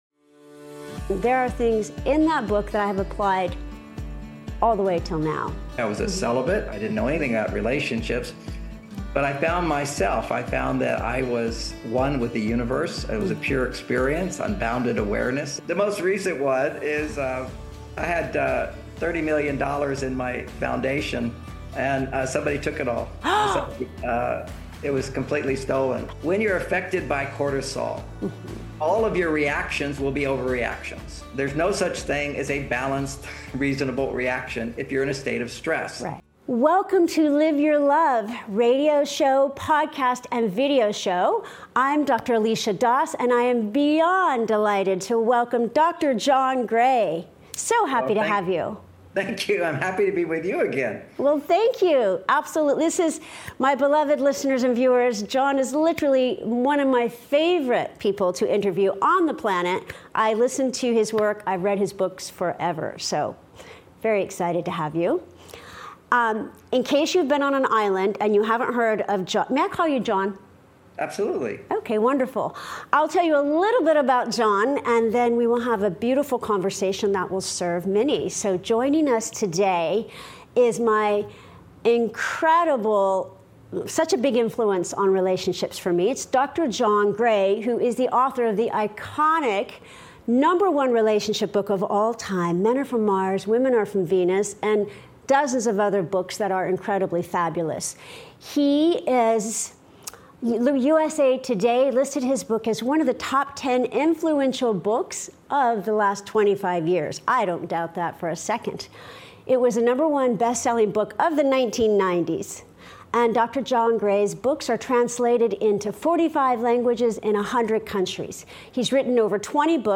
Ep.39: John Gray - Love, Light & Hormones – A Conversation You’ll Never Forget (; 13 Jul 2025) | Padverb
🧬 And he reveals the profound link between hormones and happiness—and how understanding them may be the missing piece in our healing. 💫 Stay to the end for a powerful guided meditation.